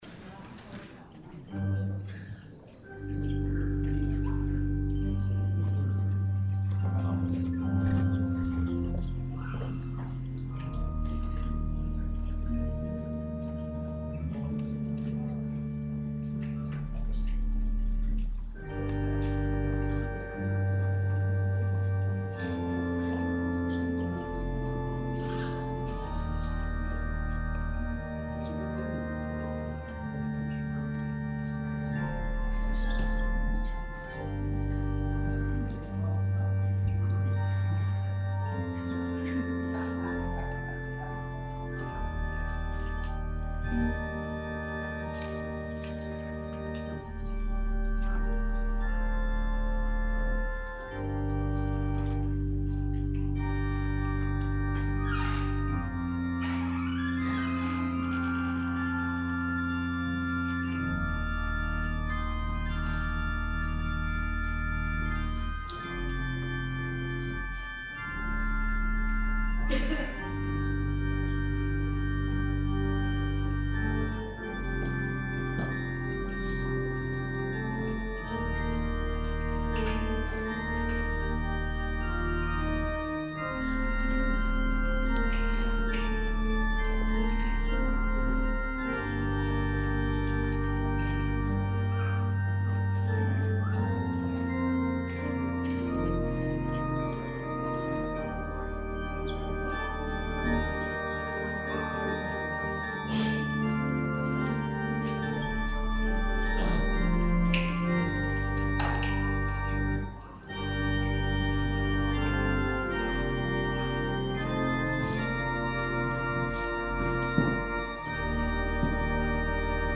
This Good Friday Vespers service, held at the First Congregational Church of Brimfield, was a joint service with the Wales Baptist Church.